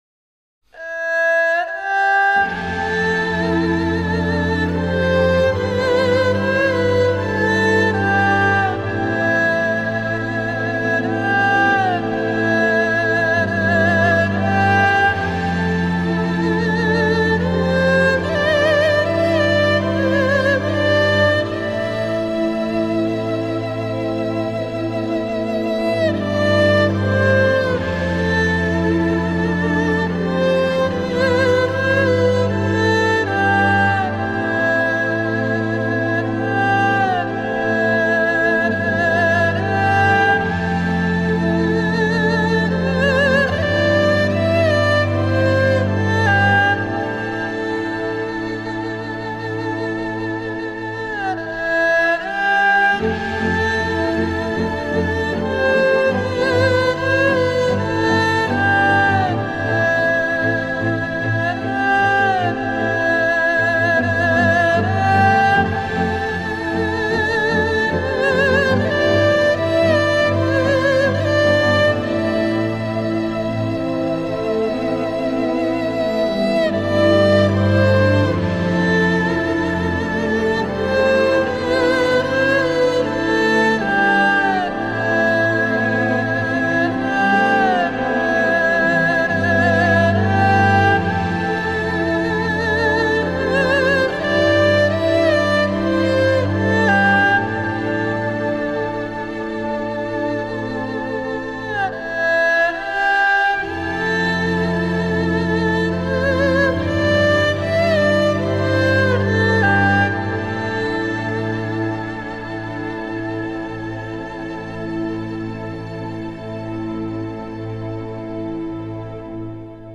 配乐方面运用了二胡、三味線、箏、篠笛等东方独有的乐器。
空灵缥缈是这张专辑的主旋律，其中也不乏具有现代感的节奏和民族化的韵律，总之是一张可听性极高的专辑。